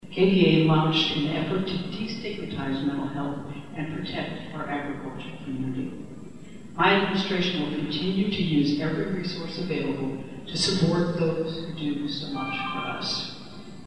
Governor Kelly addresses the 2022 Kansas Ag Summit in Manhattan